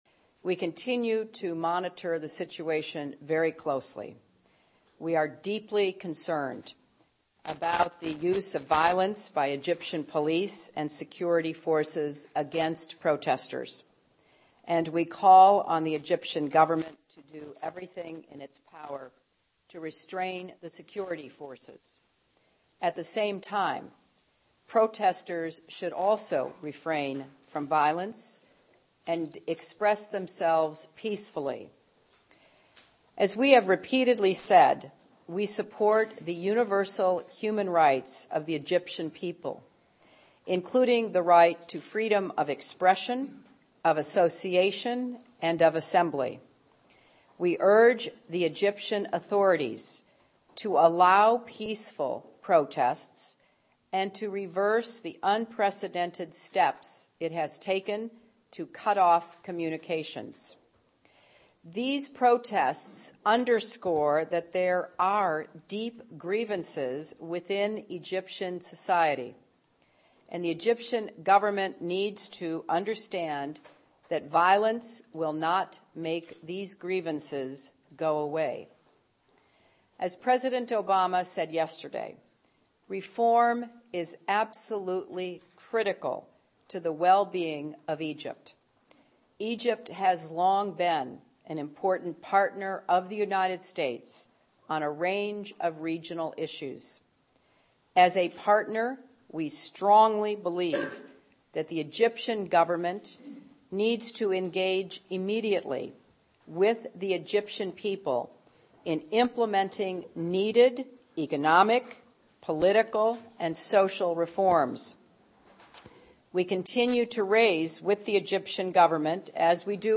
Hillary Clinton statement on Egypt, Jan. 28, 2011
Clinton_Remarks_-_Egypt-32b.mp3